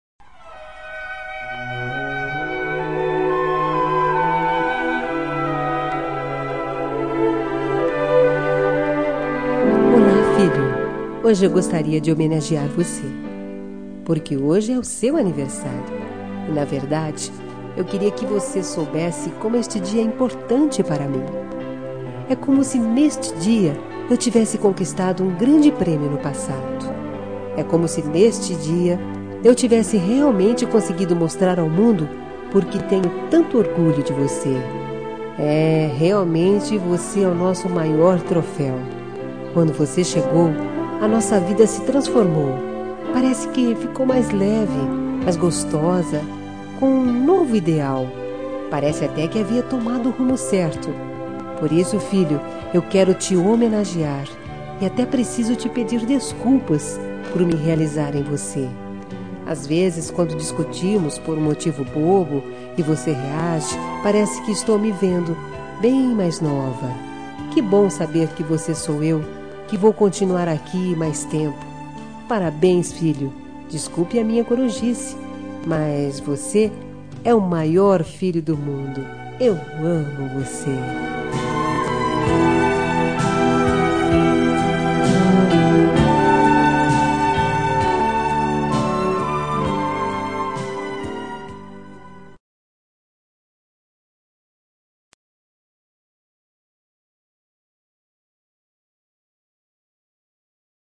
Telemensagem de Aniversário de Filho – Voz Feminina – Cód: 1823 – Linda